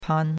pan1.mp3